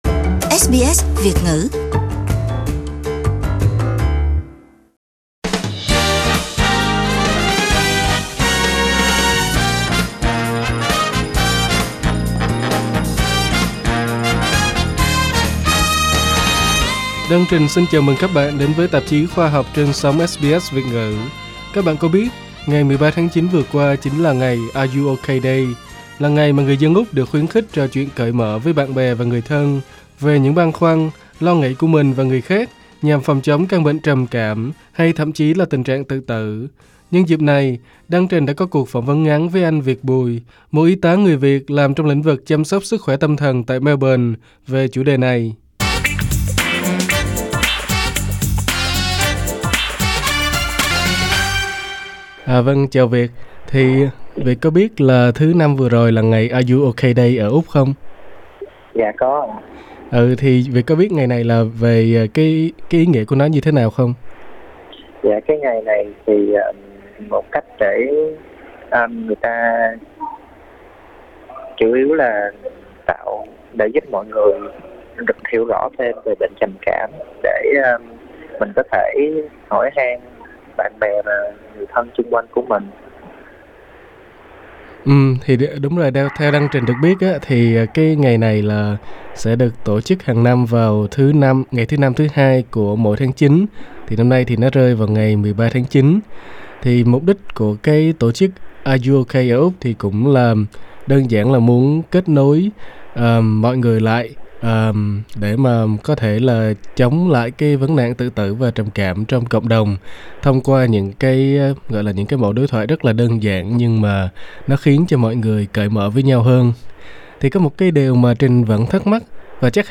SBS Vietnamese phỏng vấn